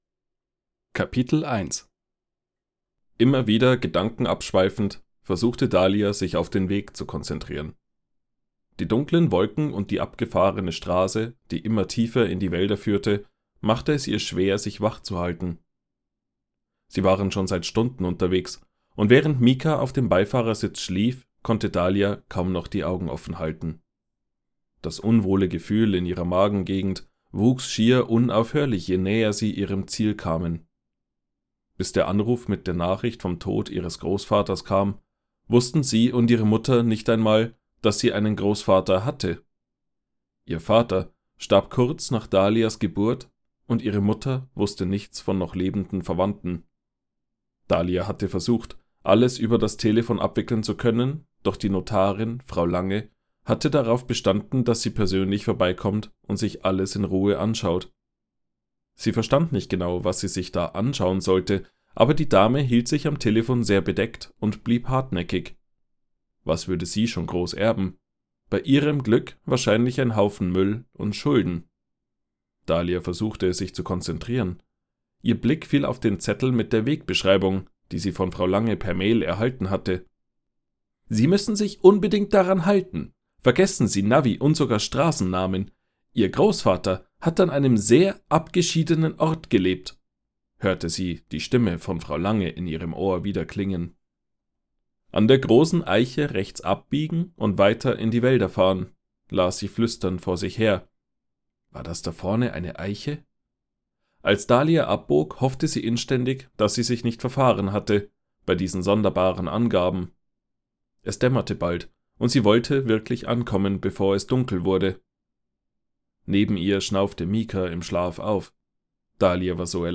Hörbuch | Fantasy